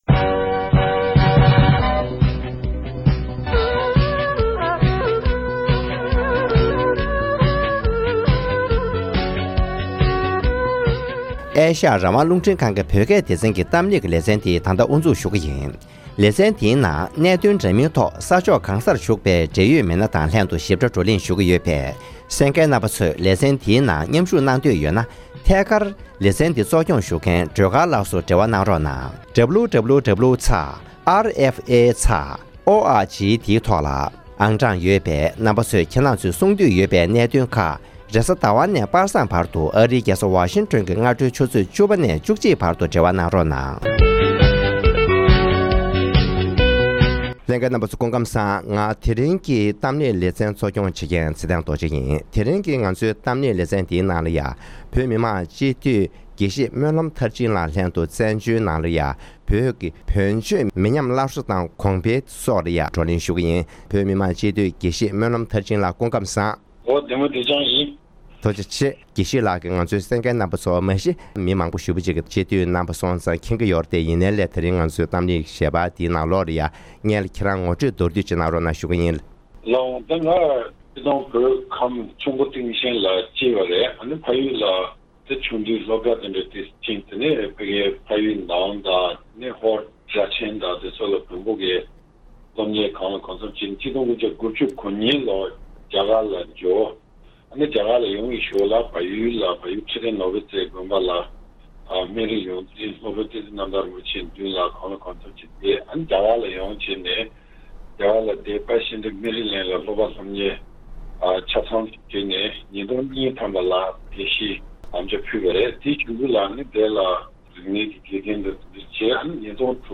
ཐེངས་འདིའི་གཏམ་གླེང་ལེ་ཚན་ནང་།